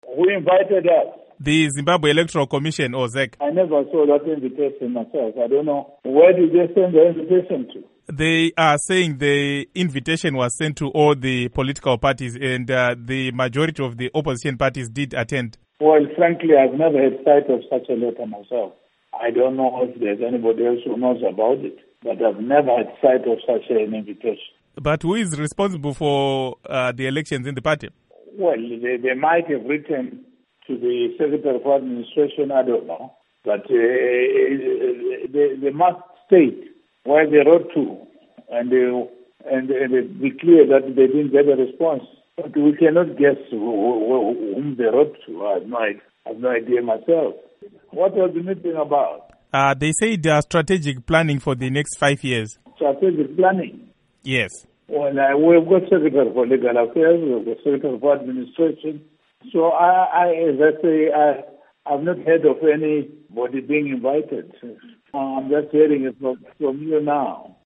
Interview With Simon Khaya Moyo on Zanu PF Boycotting ZEC Strategic Meeting